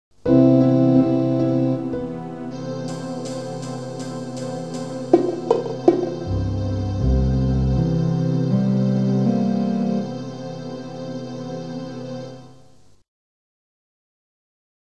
Daraus ergibt sich eine rhythmische Struktur eines 4/4 Taktes, die als horizontales Raster über die gesamten Fassaden gelegt wird.
Dies ergibt eine temperierte Rasterung von 4 Oktaven und zwei Halbtönen.